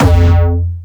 Jumpstyle Kick 10
13 F2.wav